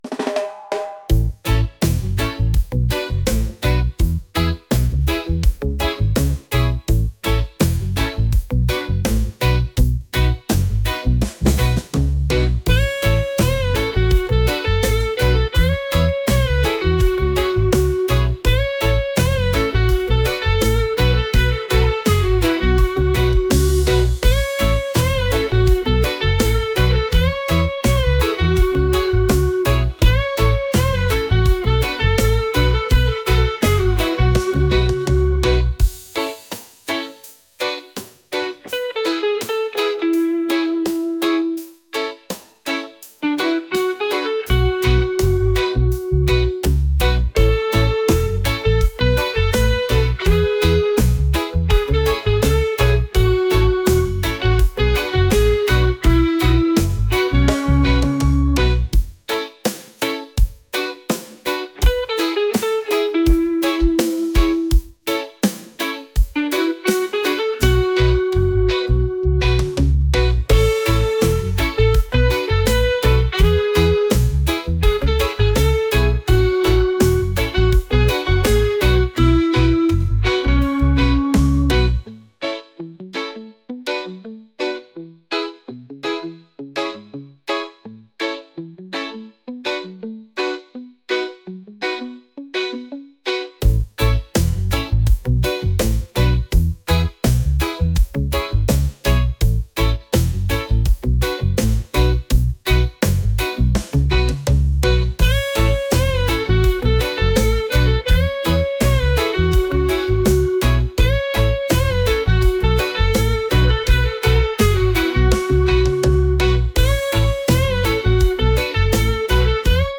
reggae | pop | soul & rnb